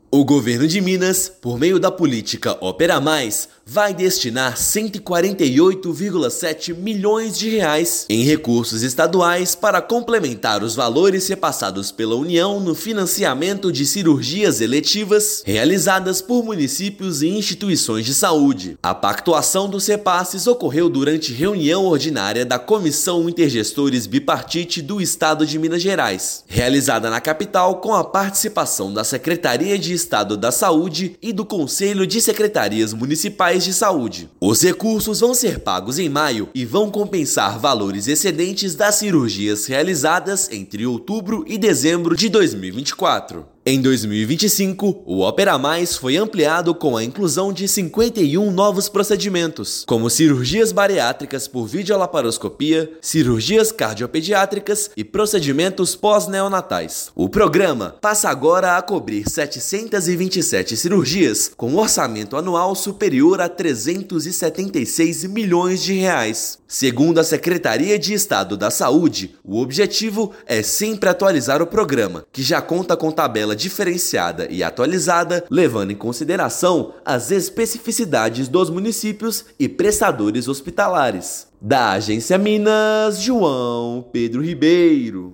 Recursos estaduais vão complementar o valor federal e cobrir cirurgias realizadas entre outubro e dezembro de 2024 que excederam o teto de financiamento. Ouça matéria de rádio.